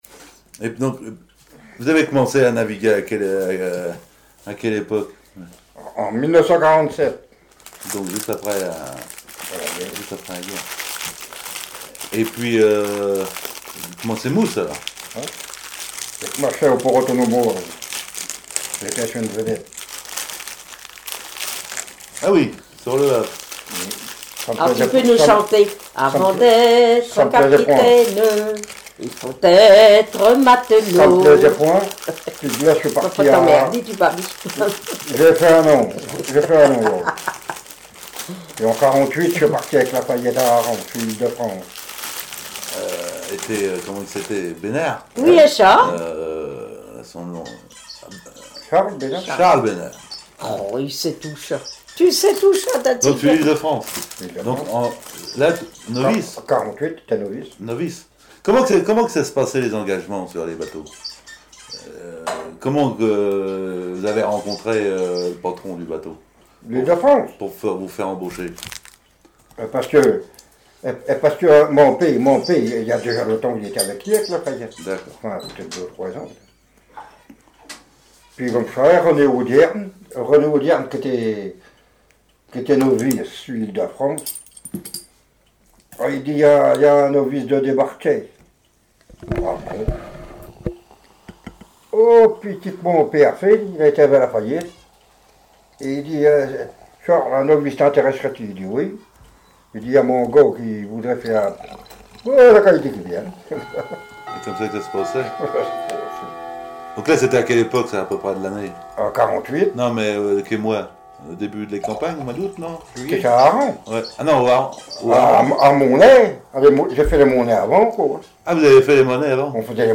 Témoignages sur le métier de marin au commerce et au chalut
Catégorie Témoignage